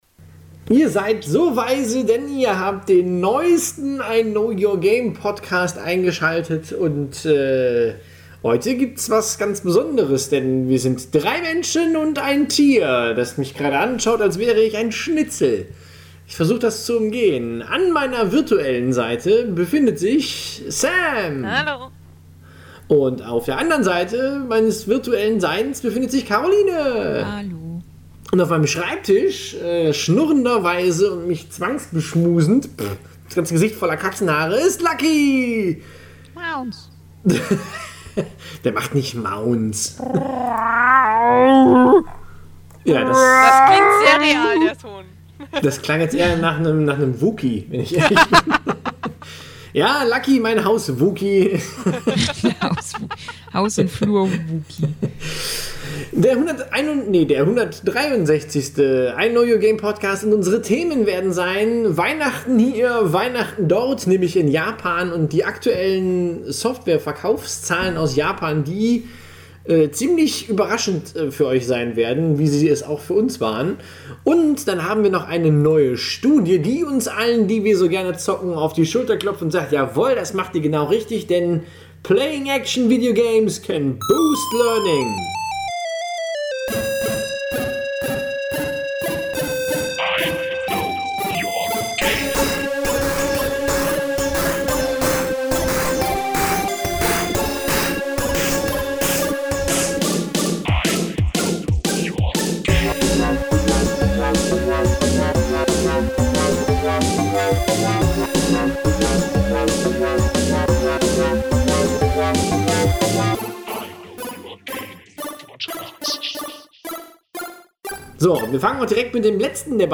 Dann wird es euch zumindest nicht mehr völlig überraschen, dass plötzlich ein Gong ertönt…